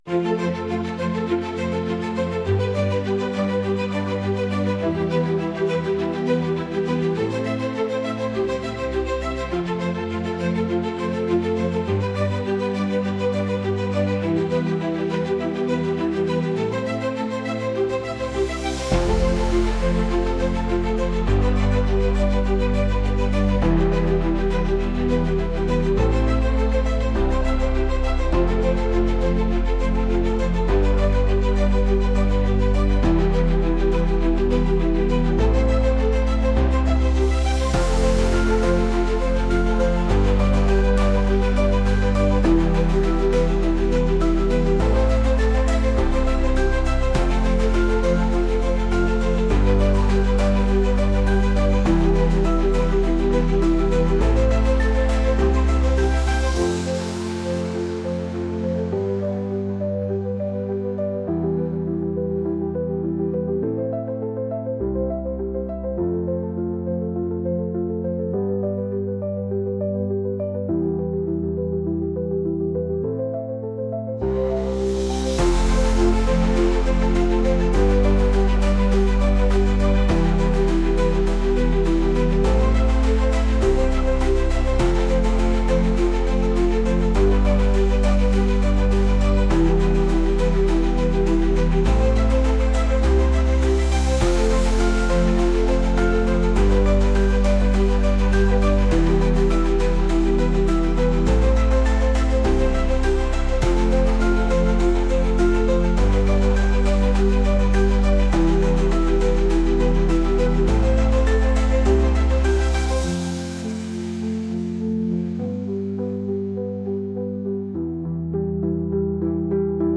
Gli studenti hanno sperimentato con suoni che potessero sembrare alieni e non convenzionali, ma che al contempo riflettessero il tono emotivo della storia.
Possibili colonne sonore